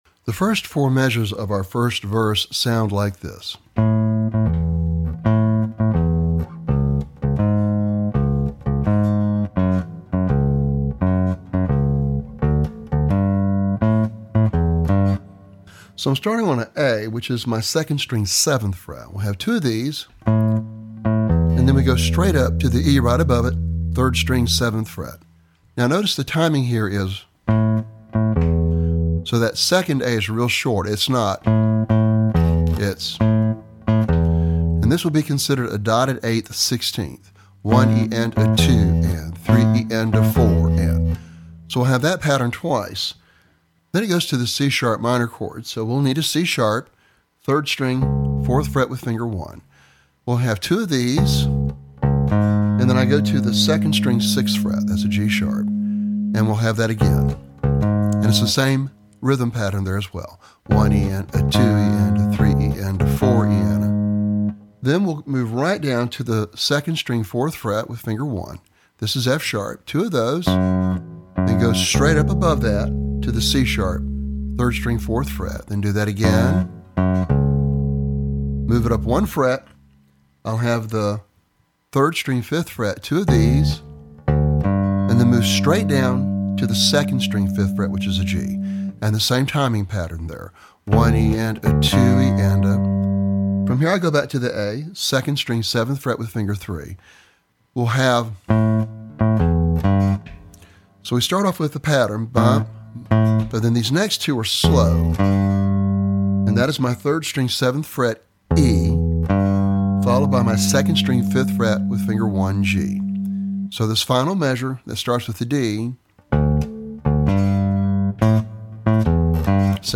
Lesson Sample
For Bass Guitar.